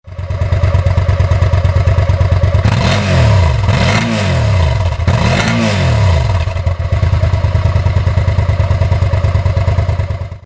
Suzuki SV650. 650cc med originalsystem
sv650.mp3